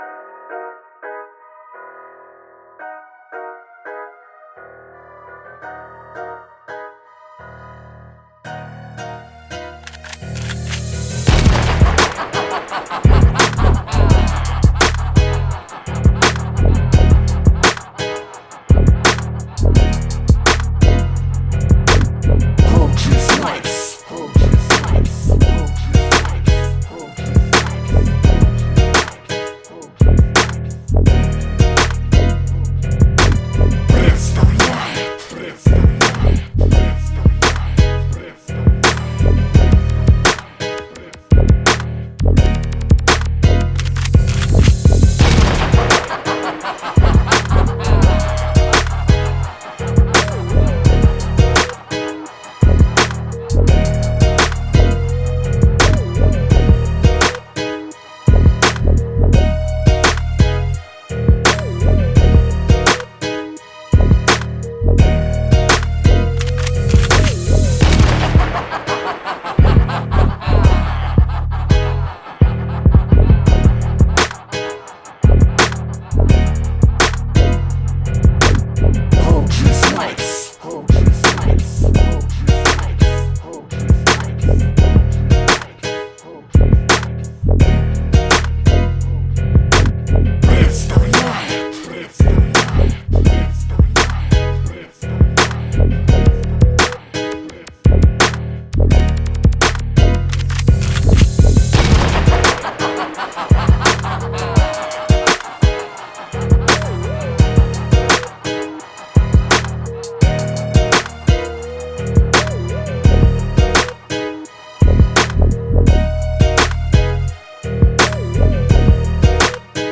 Chicano Rap Beat